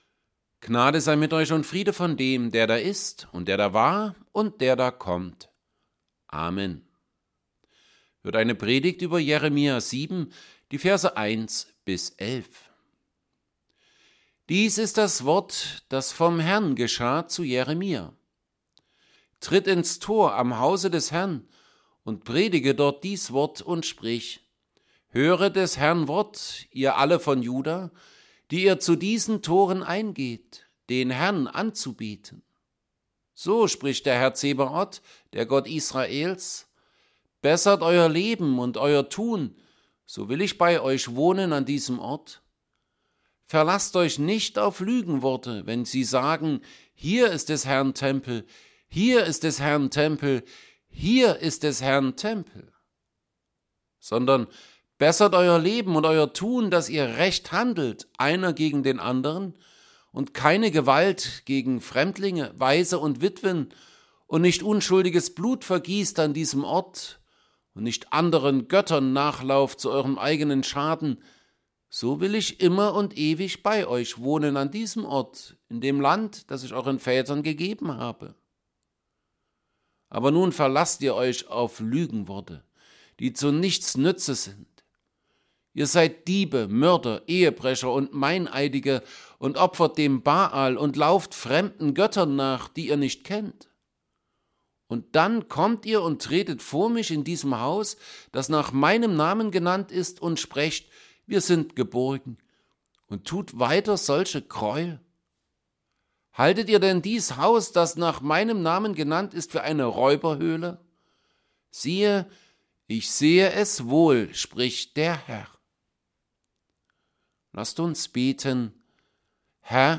10. Sonntag nach Trinitatis
Predigt_zu_Jeremia_7_1b11.mp3